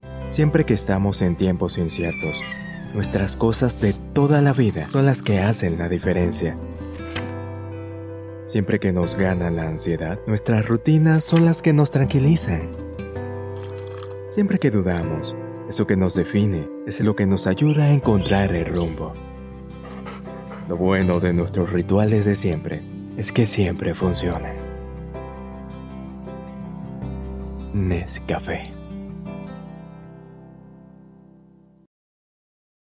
Sprechprobe: Werbung (Muttersprache):
I will recording spanish male voice over.